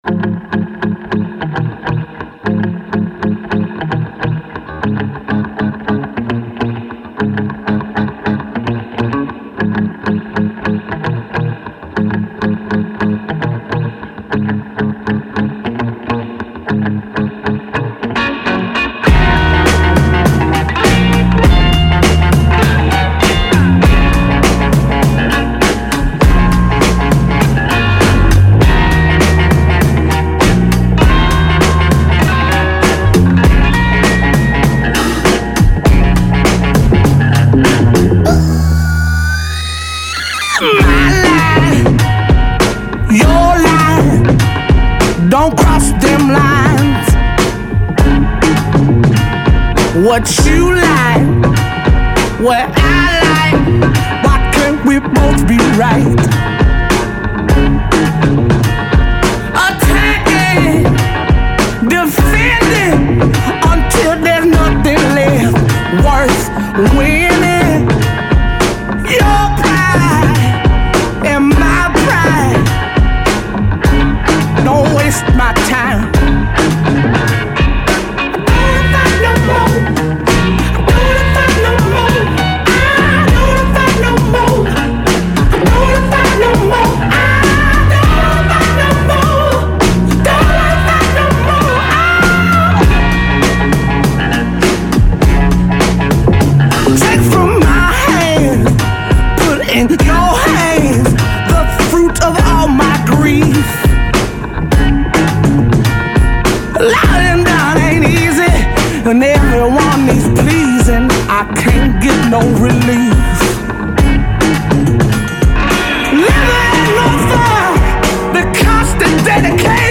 Солист-женщина !!!